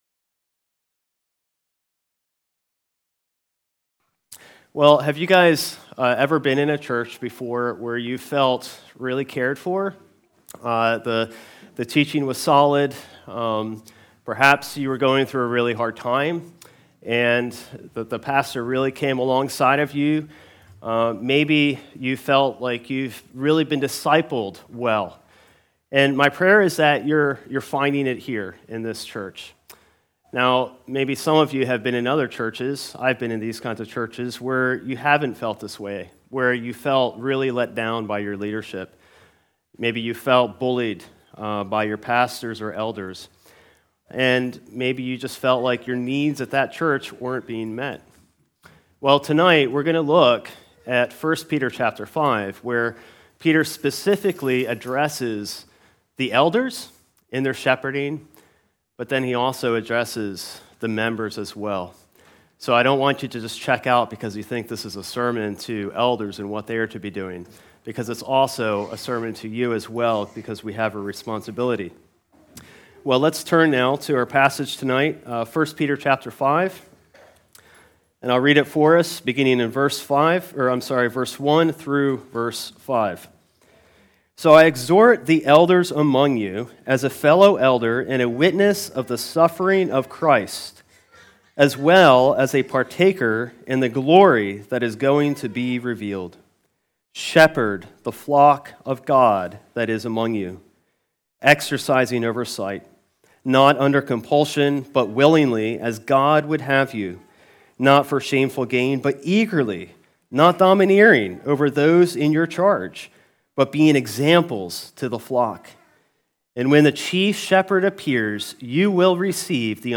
Ordination Sermon | 1 Peter 5:1-5